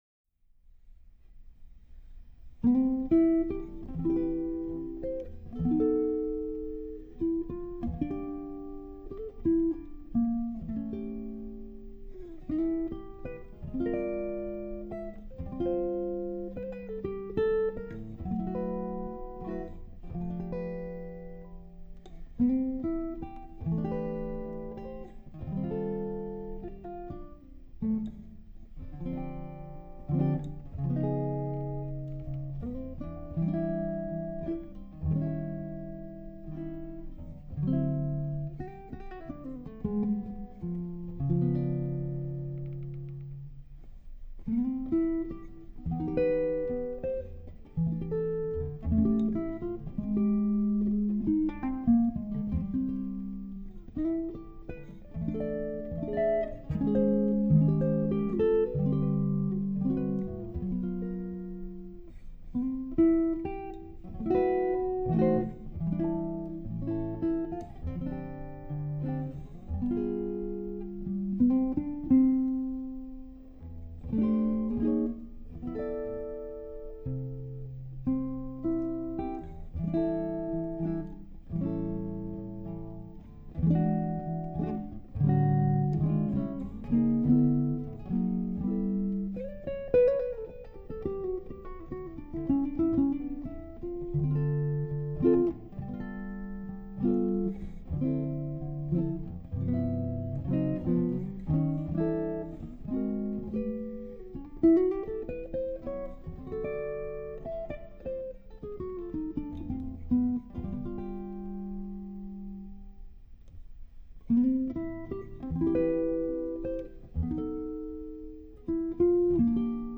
Solo Jazz Guitar
recorded with a D'Angelico NYL-II